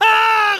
Jean-Michel Scream
jean-michel-scream.mp3